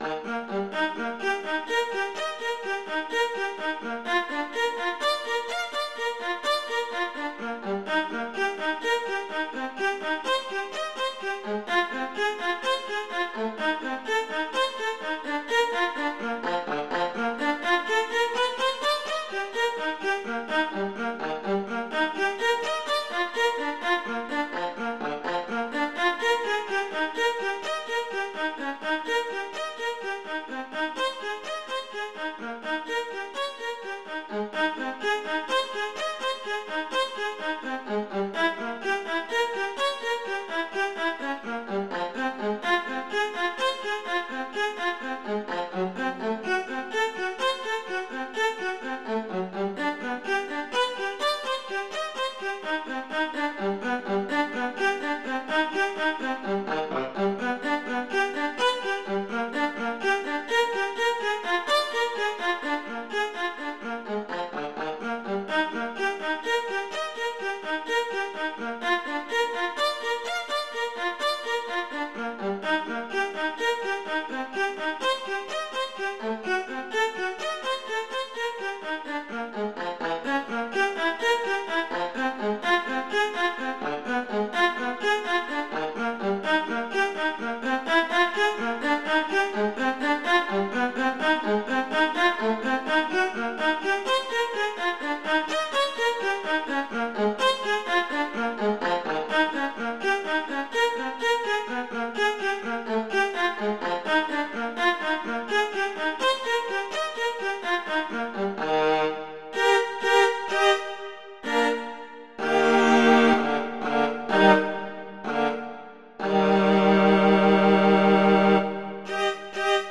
Instrumentation: viola solo
classical, instructional